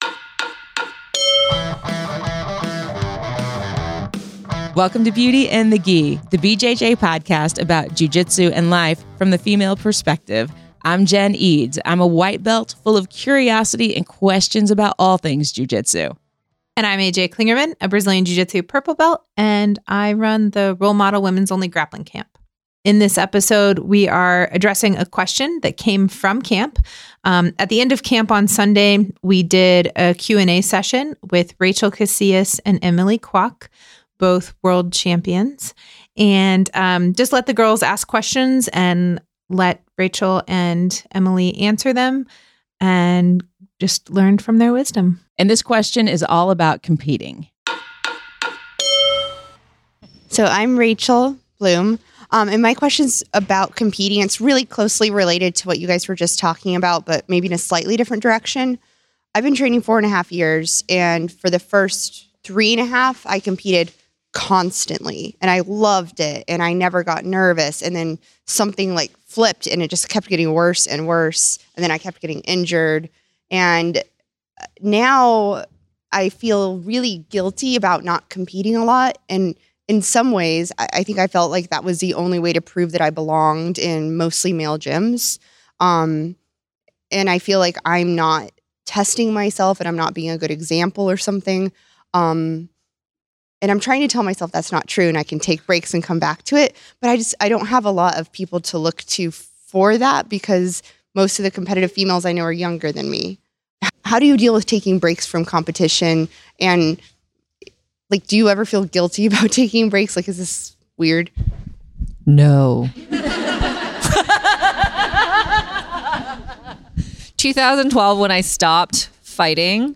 Q & A session